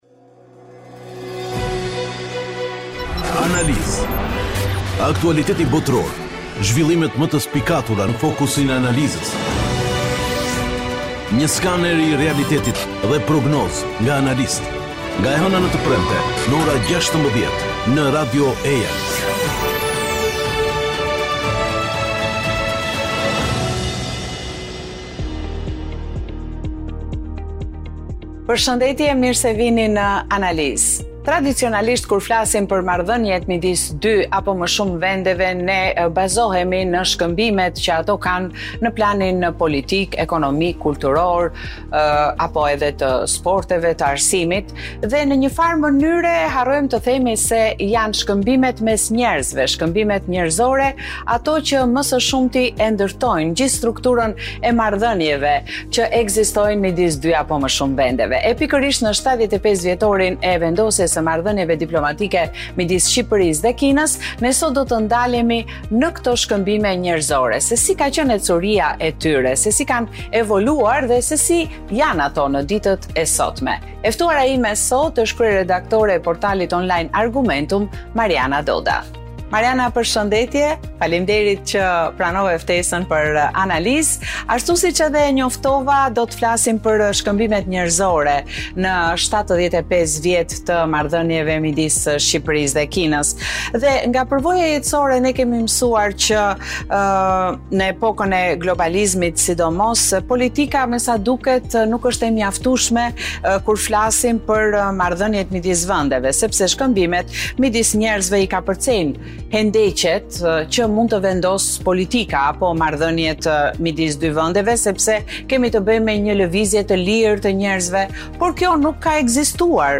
Aktualisht shkëmbimet studentore dhe turistike po ndikojnë në njohjen reciproke të popujve tanë. Në intervistë për Grupin Mediatik të Kinës (CMG)